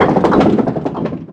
bowling04.mp3